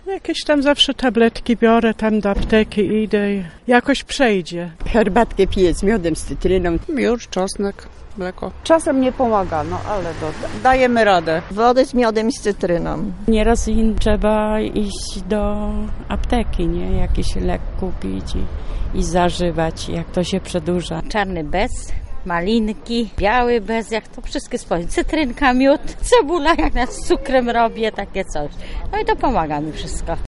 mieszkancy_sposoby_na_przeziebienie.mp3